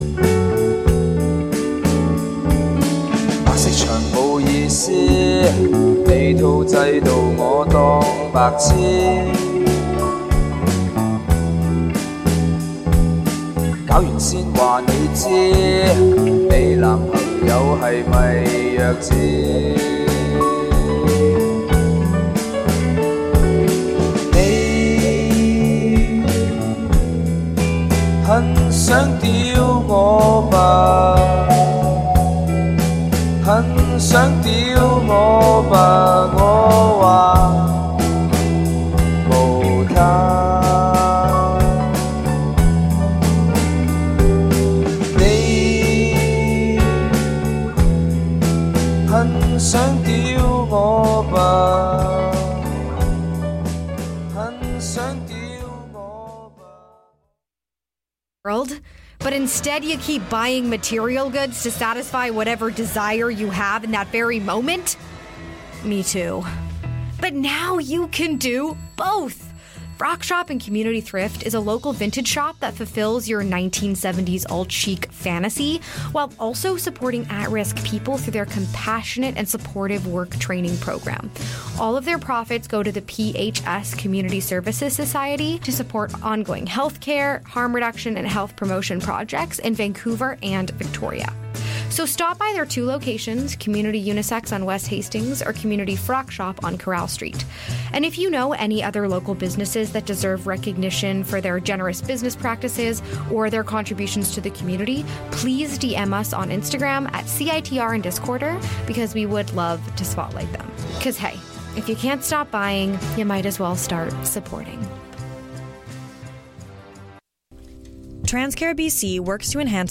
We also play some bits and pieces from the soundtrack.